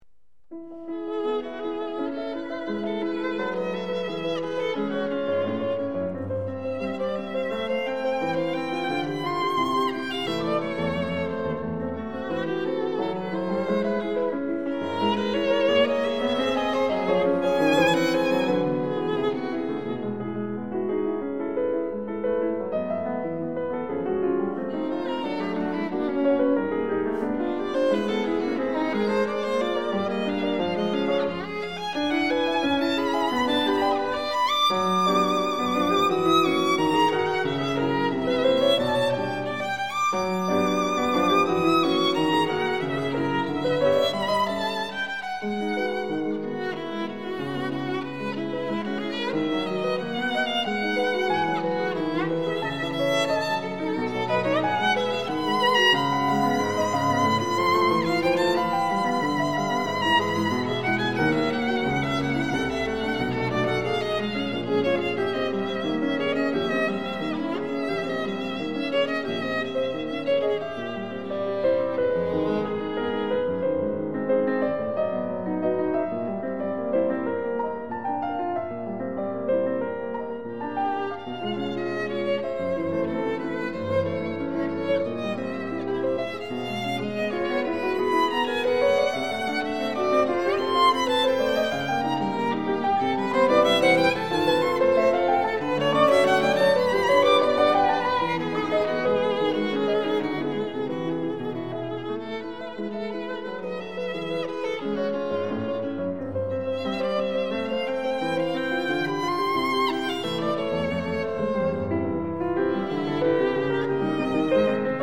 ★ 著名工程師採單點錄音，高傳真效果完美呈現！
★ 令人顫抖著迷的小提琴美音之最，發燒必備珍品！
Violin Sonata No.2 in E minor